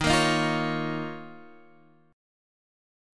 D#7sus2 chord